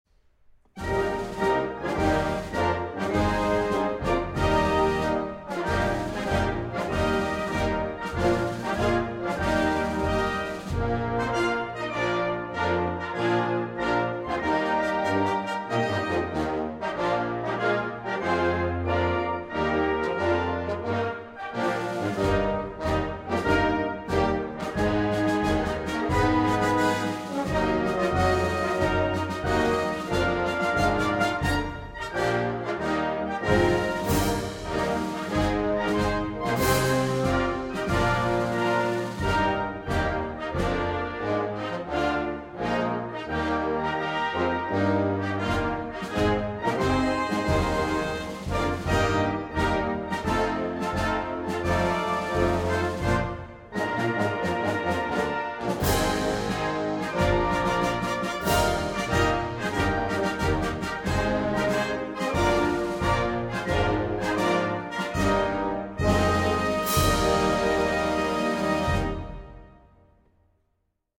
Cântico_da_Liberdade_(instrumental).mp3